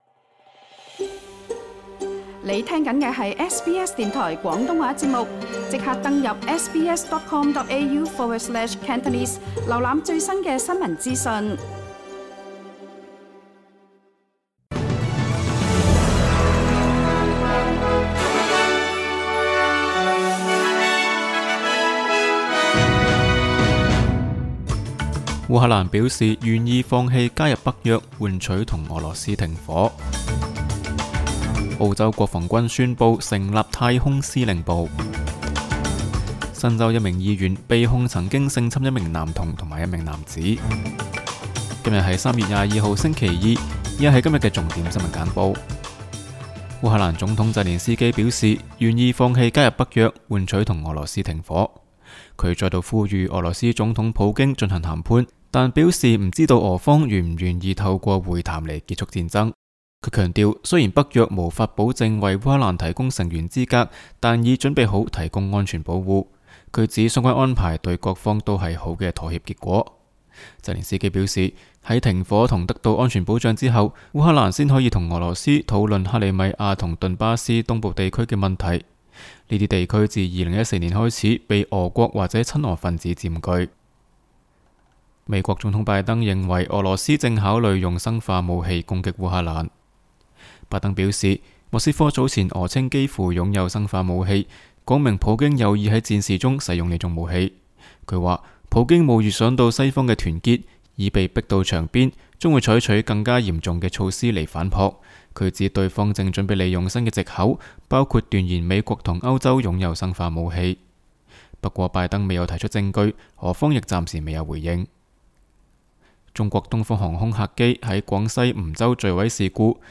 SBS 新闻简报（3月22日）
SBS 廣東話節目新聞簡報 Source: SBS Cantonese